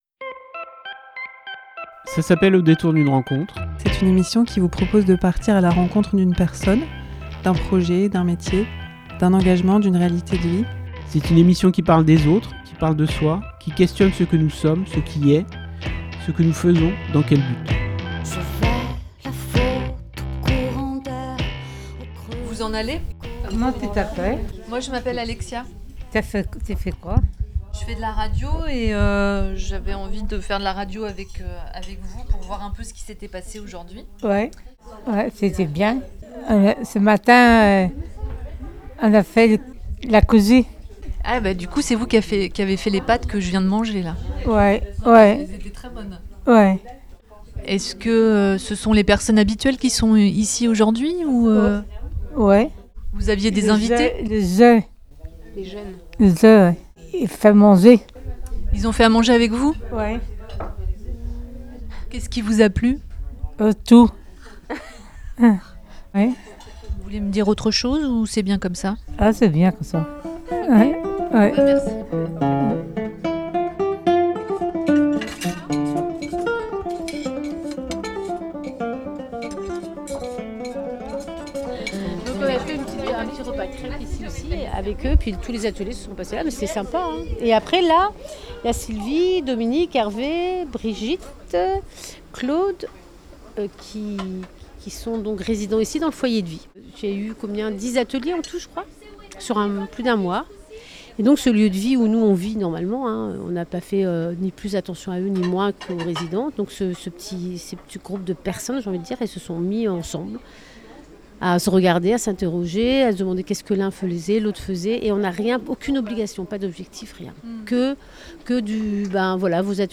Un épisode qui raconte des rencontres au foyer de vie de Prat Maria, des moments partagés, des parcours de vie, des parcours professionnels; un épisode qui donne à entendre des voix feutrées, des voix d'ailleurs, des voix douces, hésitantes, souriantes.